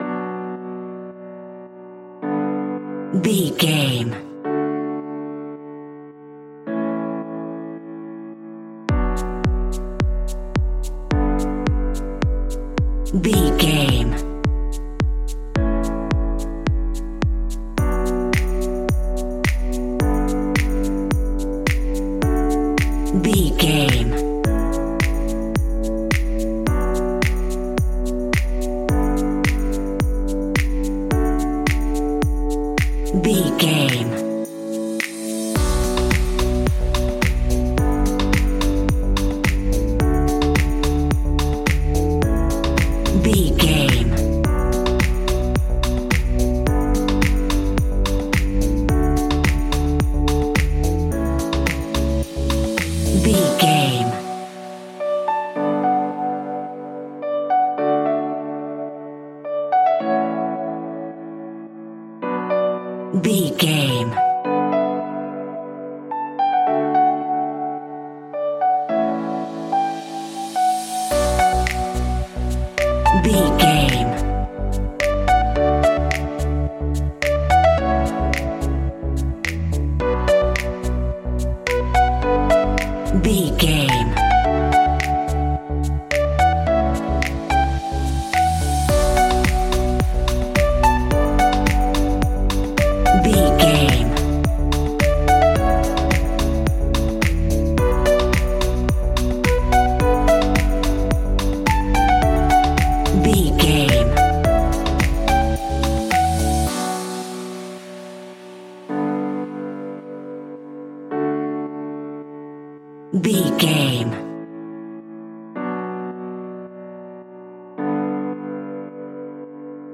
Aeolian/Minor
uplifting
energetic
repetitive
bouncy
synthesiser
drum machine
electro house
funky house
synth leads
synth bass